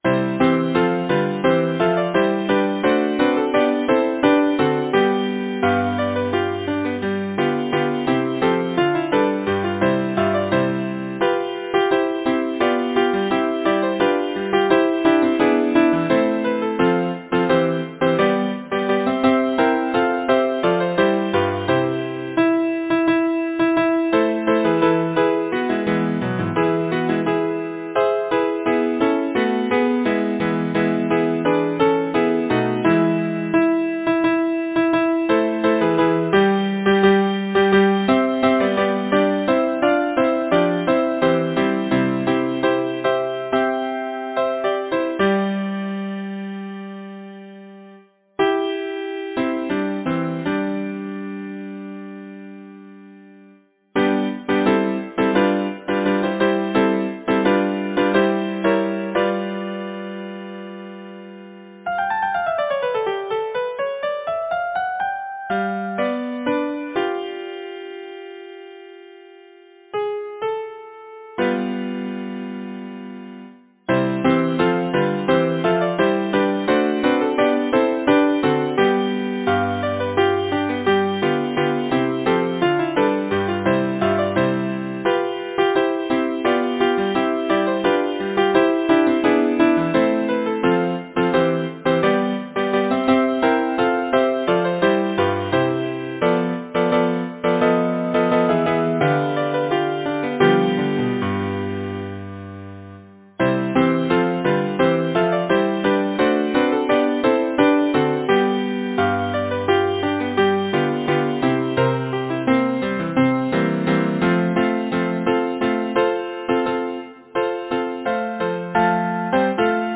Title: Spring is Coming Composer: Robert Goldbeck Lyricist: A. G. Conantcreate page Number of voices: 4vv Voicing: SATB Genre: Secular, Partsong
Language: English Instruments: A cappella